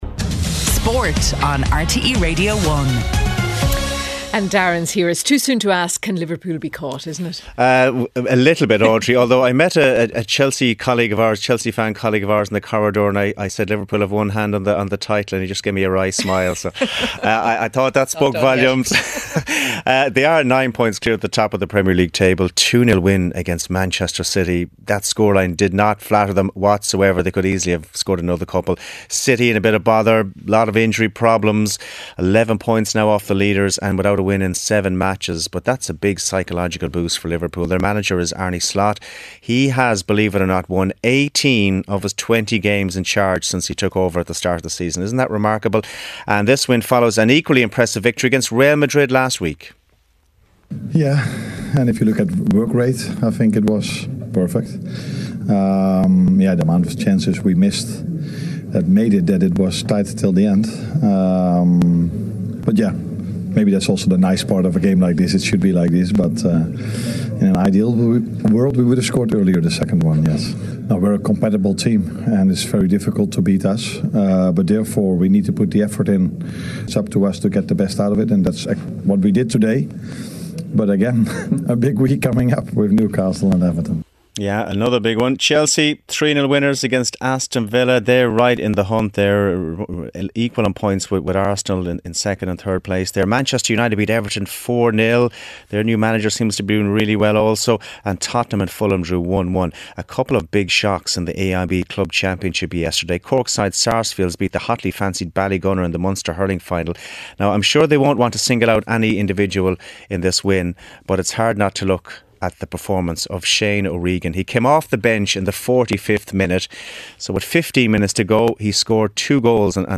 8:35am Sports News - 02.12.2024